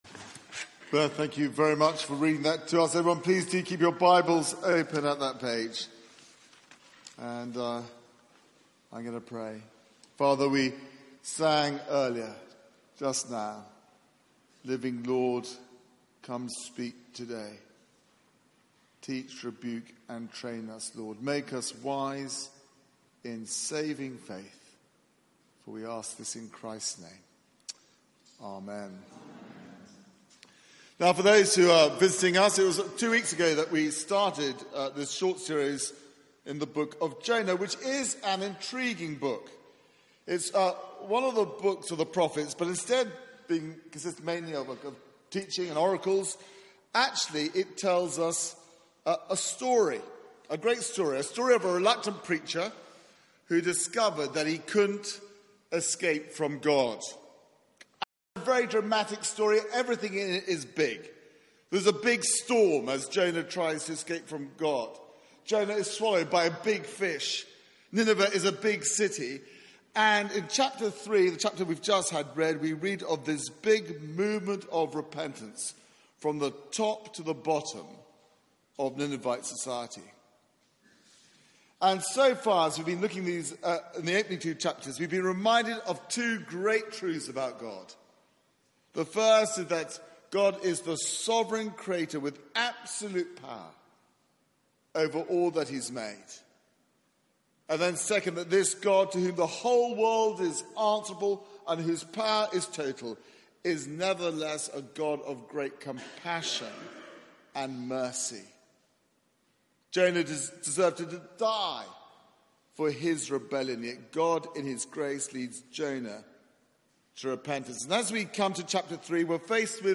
Media for 6:30pm Service on Sun 06th May 2018 18:30 Speaker
Series: Jonah Theme: Proclaiming a compassionate God Sermon Search the media library There are recordings here going back several years.